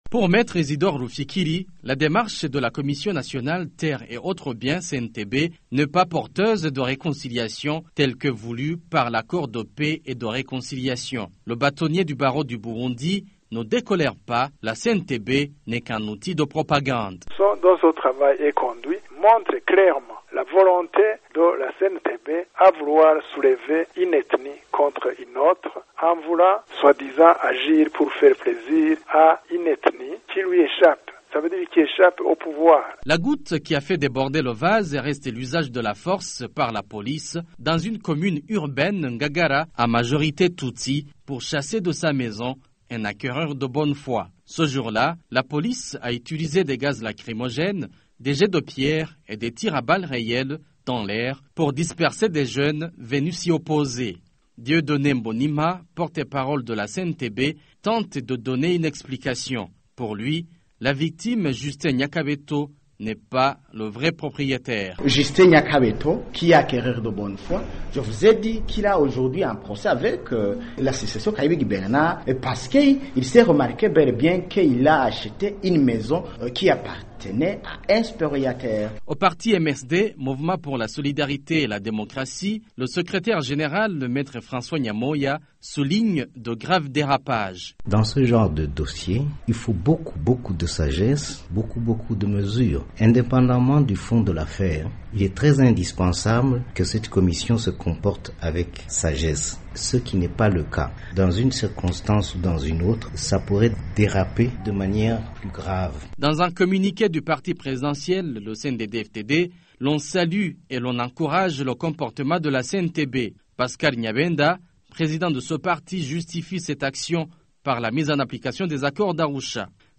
Le reportage de notre correspondant